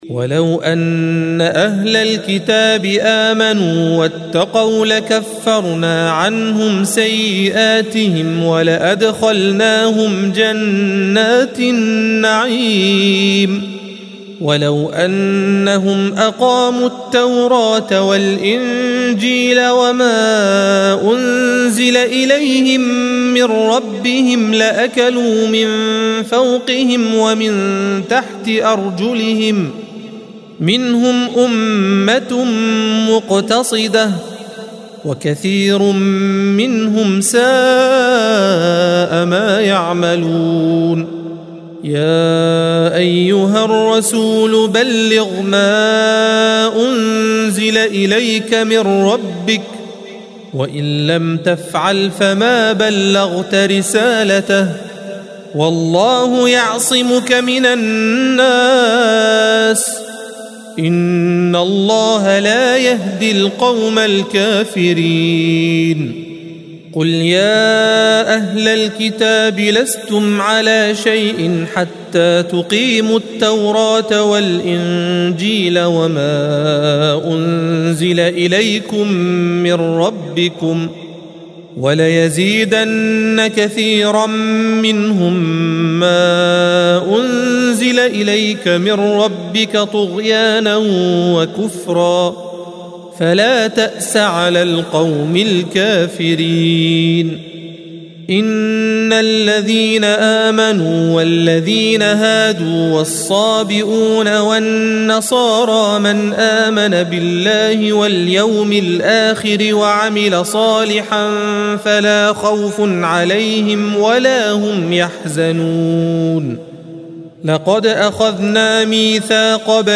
الصفحة 119 - القارئ